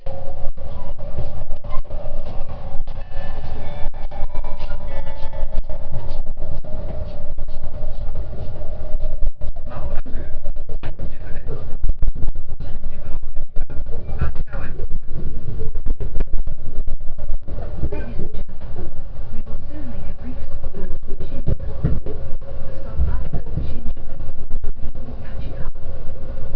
・E257系車内放送
255系のように専用のチャイムがあるわけでもなく、JR東日本の特急型車両としてはお馴染みのチャイムと放送です。尚、何故か錦糸町到着と新宿到着の放送は上手く録れずボコボコした音声になってしまっているので、お聞きの際はご注意ください。